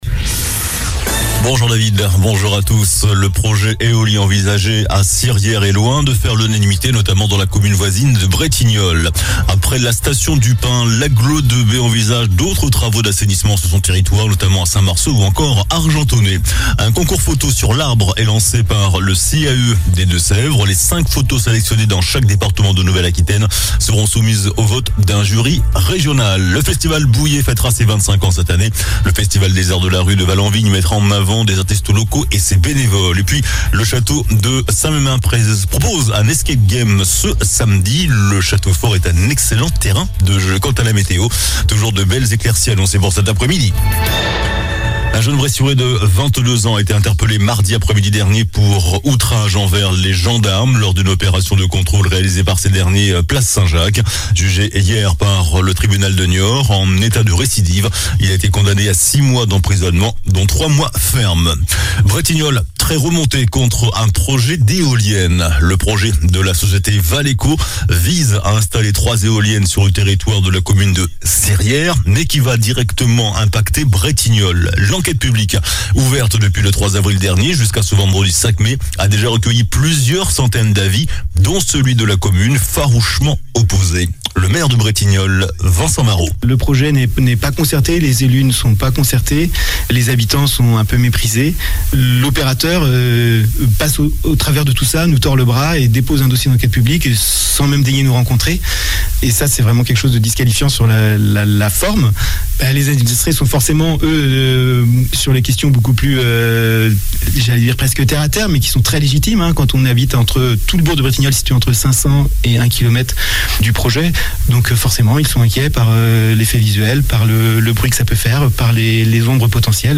JOURNAL DU JEUDI 04 MAI ( MIDI )